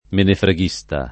vai all'elenco alfabetico delle voci ingrandisci il carattere 100% rimpicciolisci il carattere stampa invia tramite posta elettronica codividi su Facebook menefreghista [ menefre g&S ta ] s. m. e f. e agg.; pl. m. ‑sti